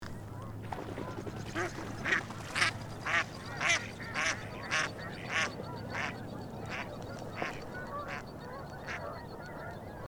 American Black Duck
Voice
Black ducks sound similar to a mallard: males make a kwek-kwek sound, while females quack.
american-black-duck-call.mp3